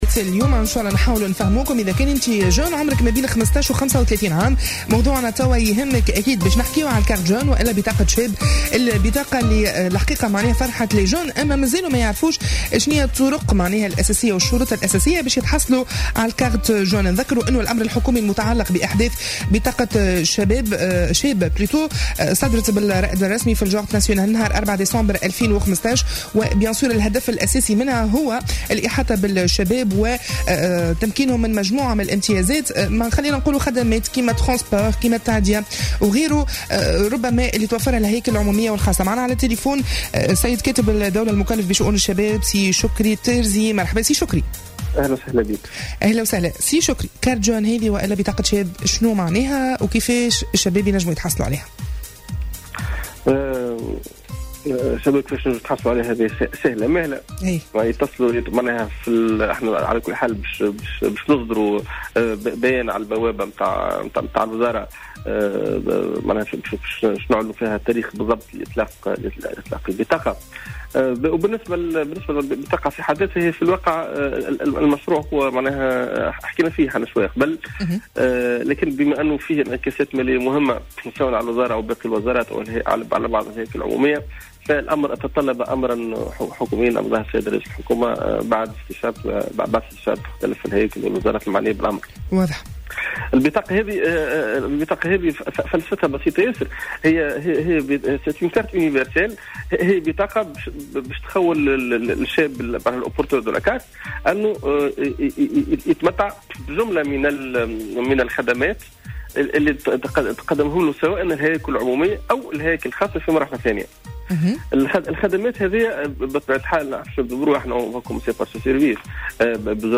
أكد كاتب الدولة المكلّف بشؤون الشباب، شكري التارزي في مداخلة له اليوم في برنامج "After Work" أنه بإمكان كل من تتراوح أعمارهم بين 15 و35 سنة الحصول على بطاقة شاب شريطة أن يكون لديهم انخراط في دور الشباب.